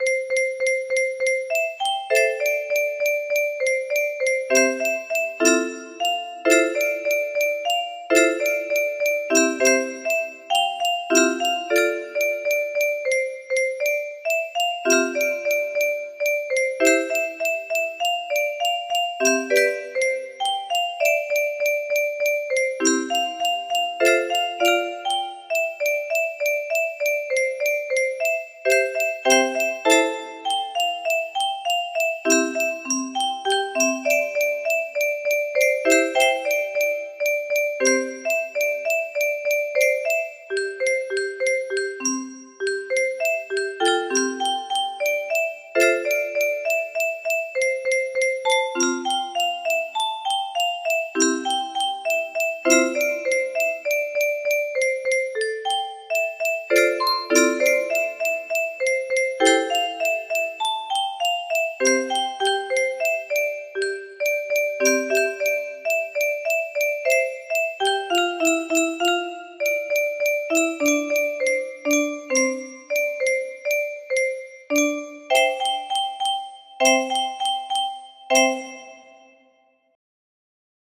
Unknown Artist - Untitled music box melody
Imported from MIDI from imported midi file (17).mid